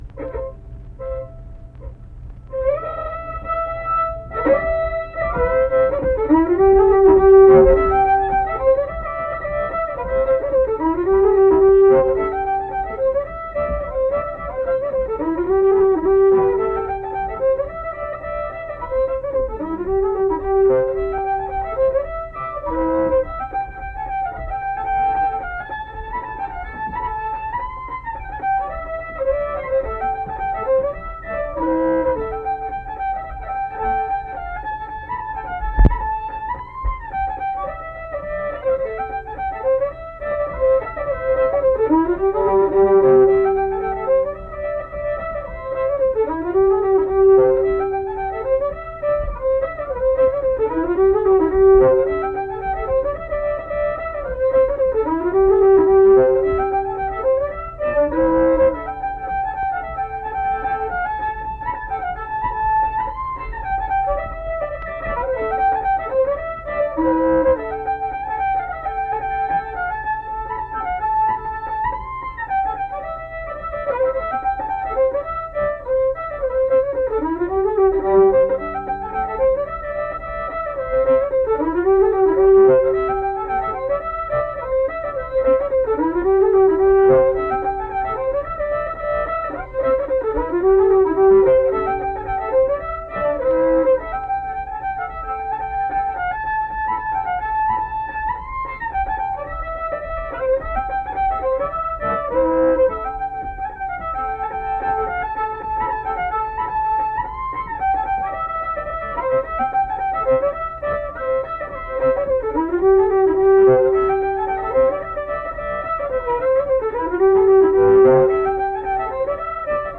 Turkey In The Straw - West Virginia Folk Music | WVU Libraries
Accompanied guitar and unaccompanied fiddle music performance
Instrumental Music
Fiddle